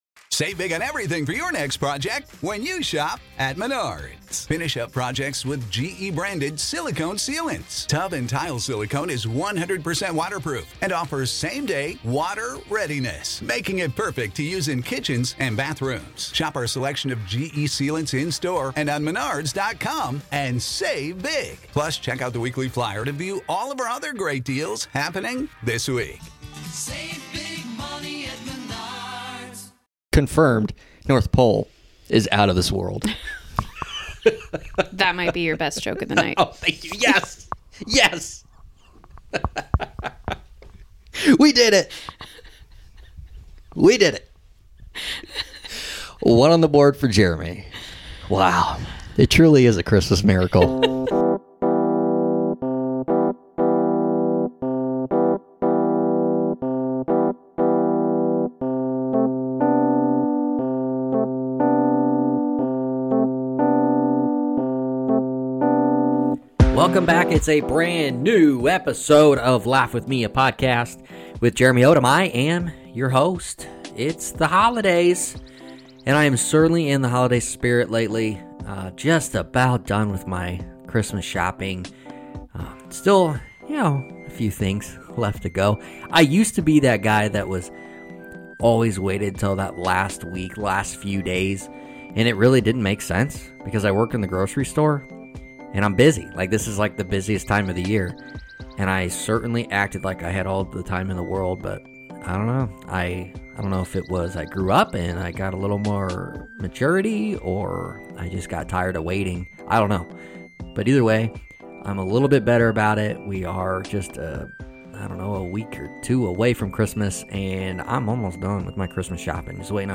Comedy, Stand-up